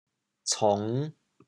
潮阳拼音“cong5”的详细信息
国际音标 [ts]
cong5.mp3